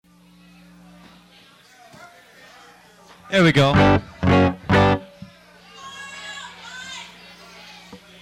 Live at Joe's Joint